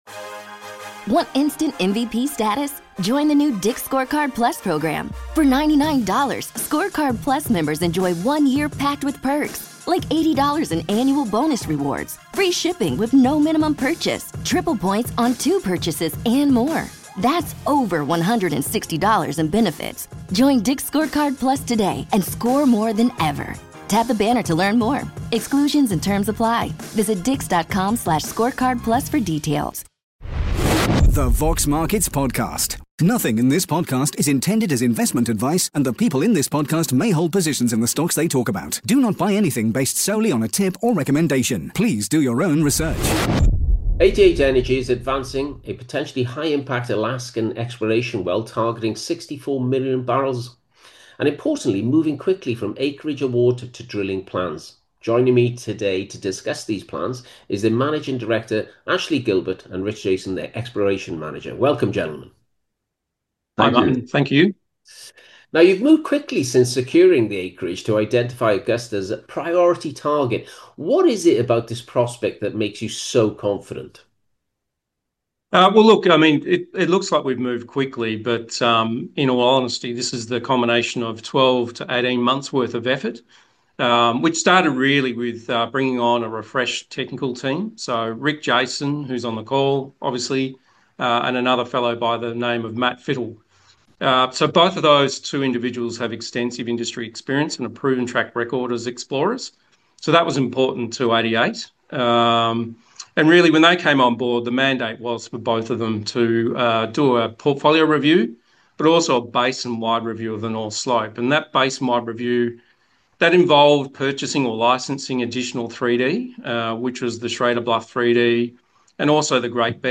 With key milestones ahead, this interview explores why management believes momentum is building and what could drive the next phase of growth for investors.